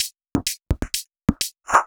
Index of /VEE/VEE Electro Loops 128 BPM
VEE Electro Loop 235.wav